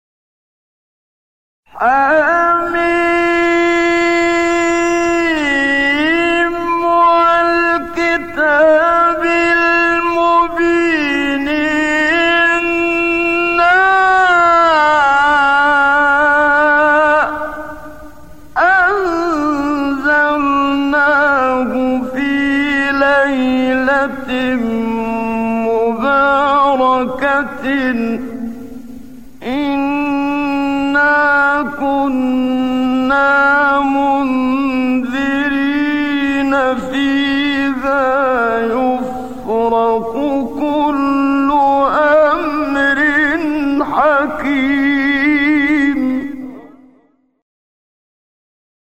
سایت قرآن کلام نورانی - چهارگاه منشاوی (2).mp3
سایت-قرآن-کلام-نورانی-چهارگاه-منشاوی-2.mp3